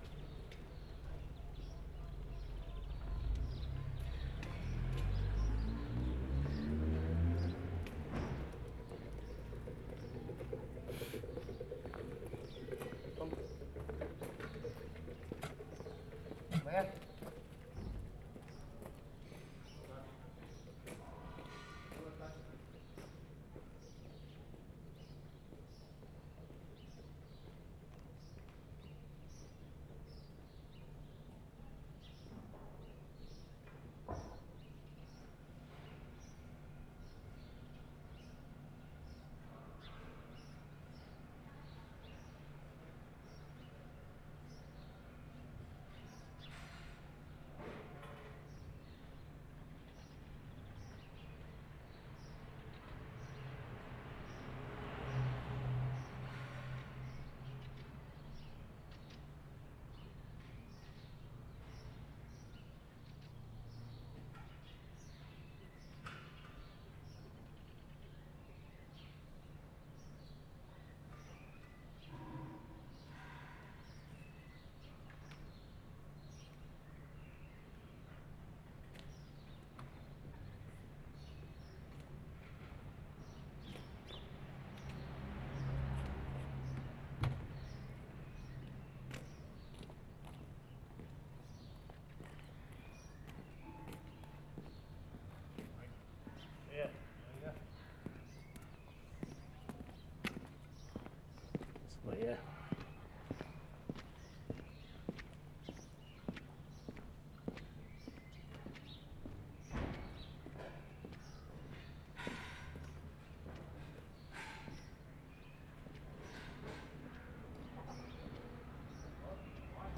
Bissingen, Germany March 4/75
AMBIENCE, MITTLERE STRASSE, with milk cans rattling in background
9. Some traffic, footsteps and greetings as people walk down street, birds, milk cans in distance.